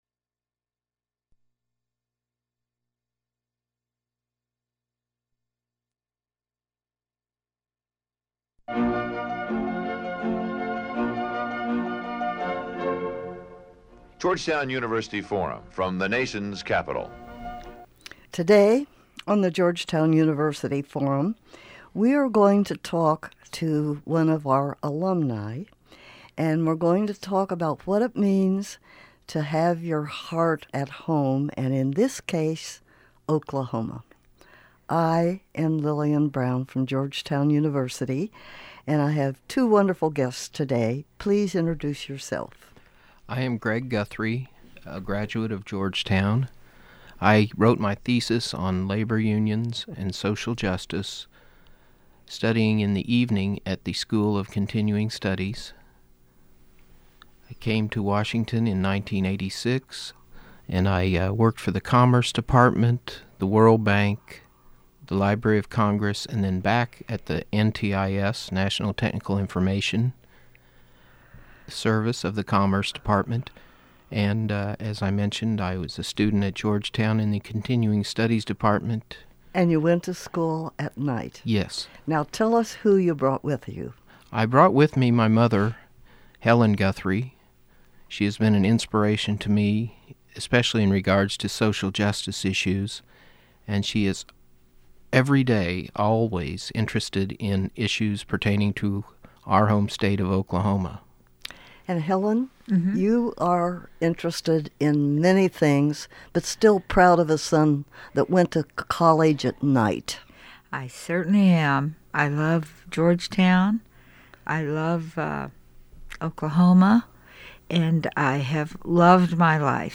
Georgetown University Forum Interview